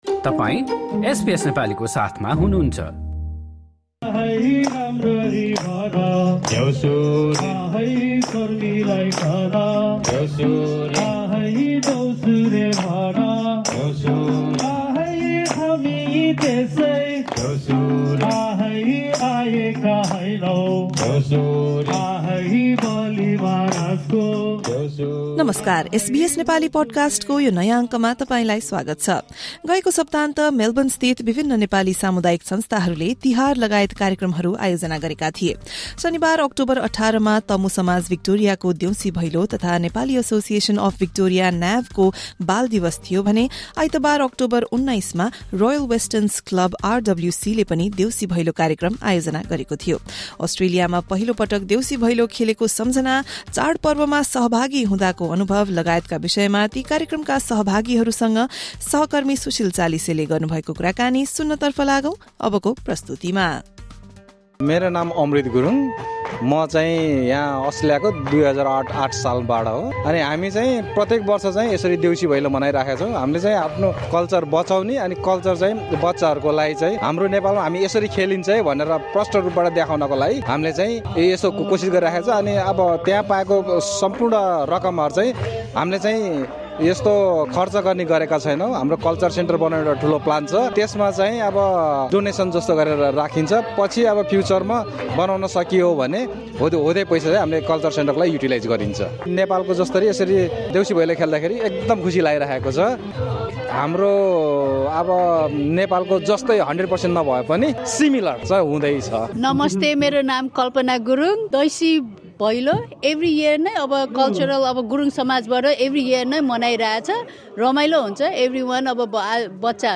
SBS Nepali spoke to community members in Melbourne about their festive experiences this Tihar at cultural programs done by various Nepali community organisations. Listen to our conversation with participants at the Deusi Bhailo program by Tamu Samaj Victoria and Children's Day celebration by Nepali Association of Victoria (NAV) on Saturday, 18 October and Tihar event by Royal Westerns Club (RWC) on Sunday, 19 October 2025.